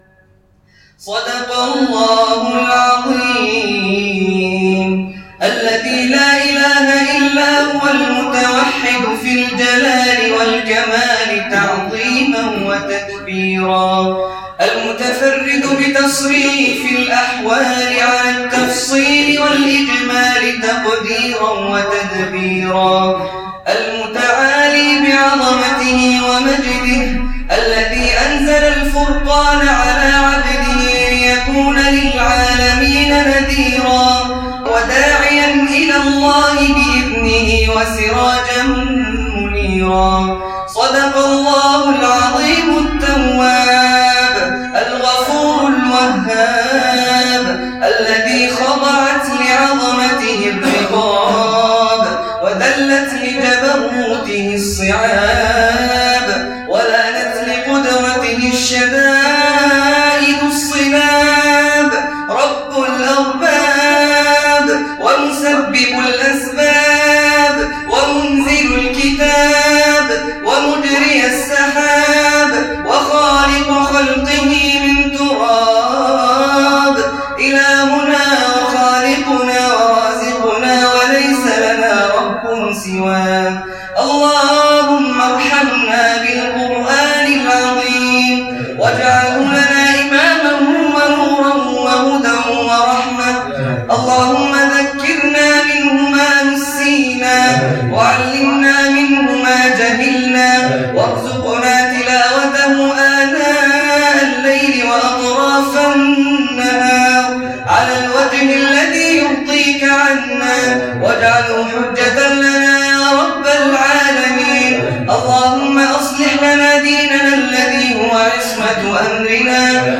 دعاء ختم القرآن رمضان 1437هـ